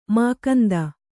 ♪ mākanda